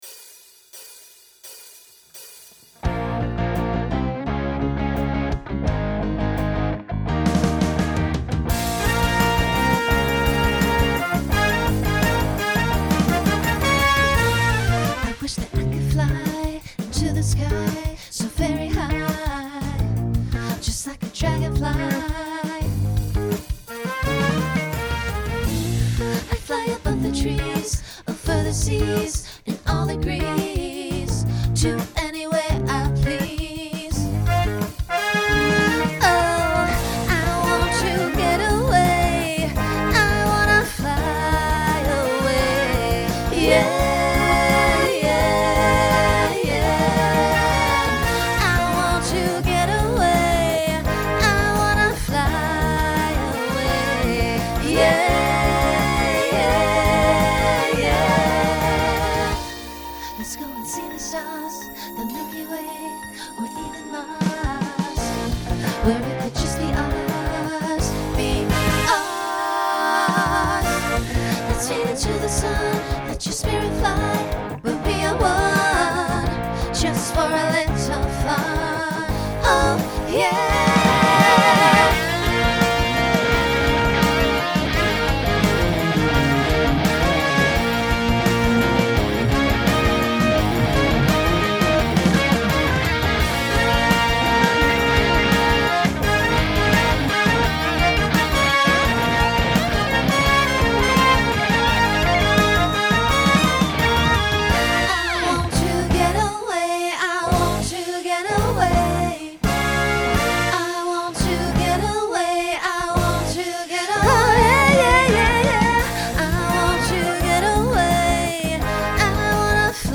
Genre Rock Instrumental combo
Opener Voicing SSA